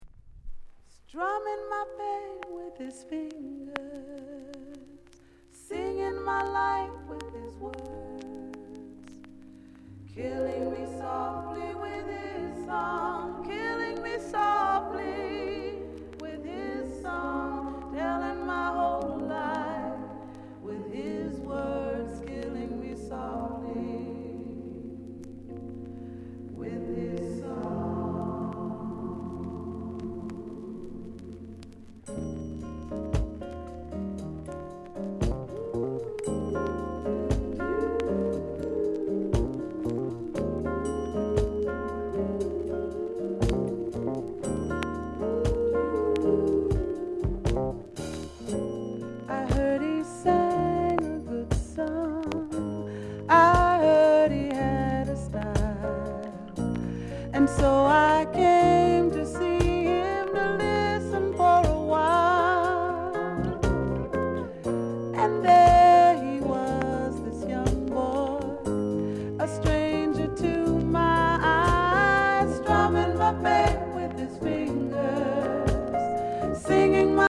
SOUND CONDITION VG(OK)